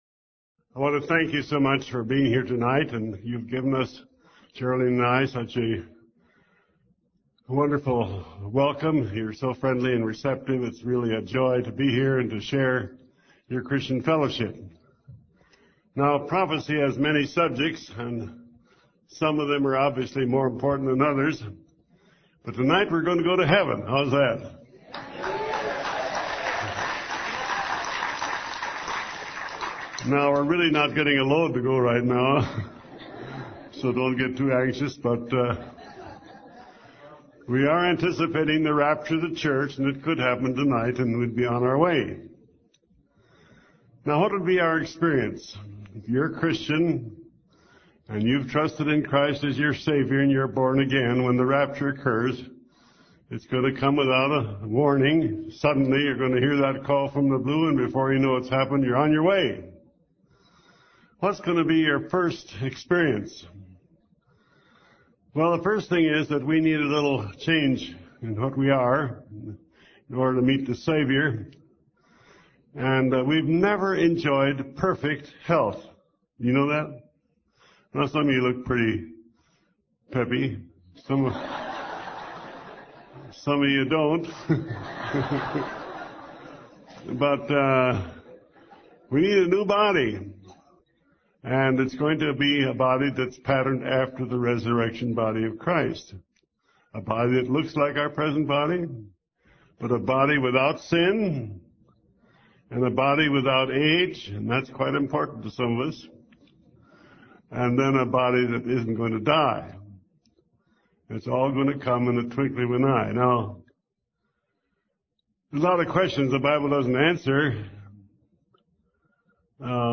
In this sermon, the preacher emphasizes the beauty and perfection of the heavenly city that awaits Christians.